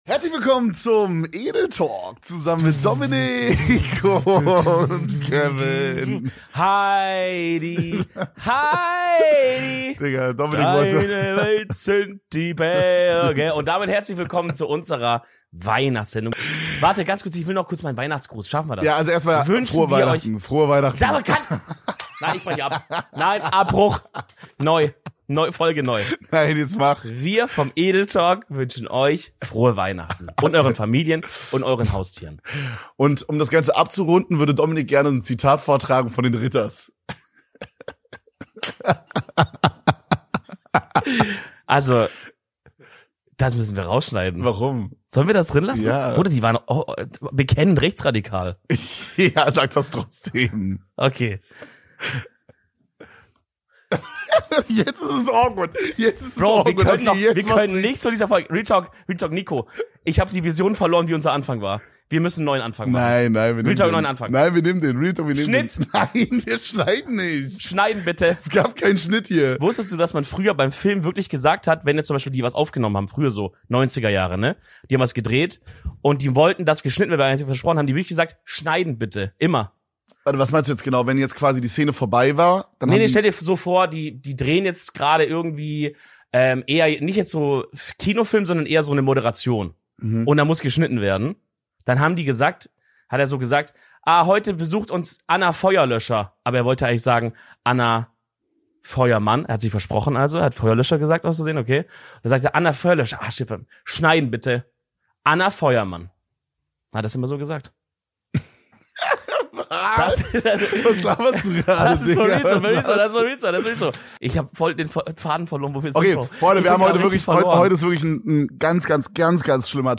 Es weihnachtet sehr im Edeltalk Studio. Anlässlich des 4. Advents, welcher zugleich Heiligabend ist, präsentieren wir euch eine gemütliche Weihnachtsfolge mit viel Schnack und lecker Raclette.